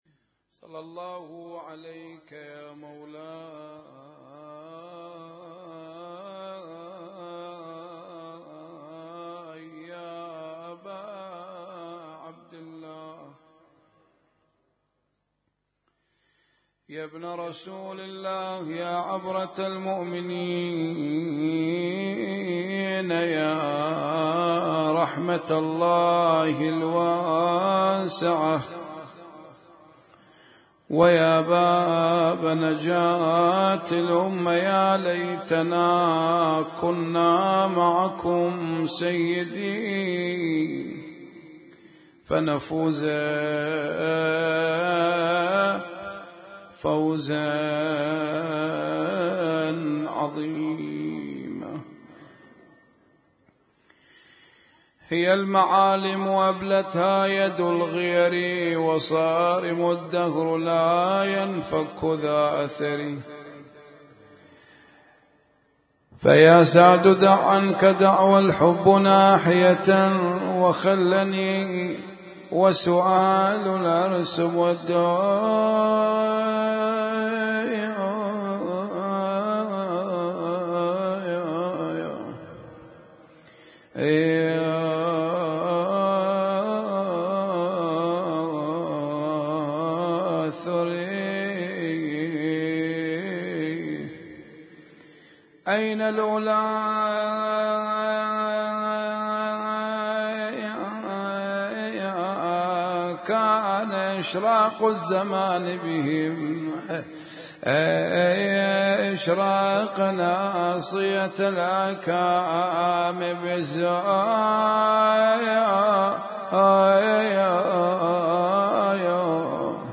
Husainyt Alnoor Rumaithiya Kuwait